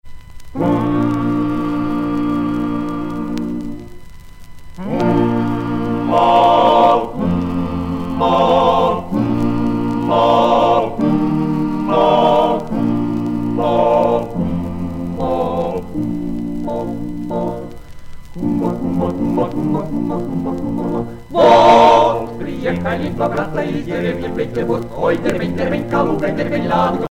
Accordion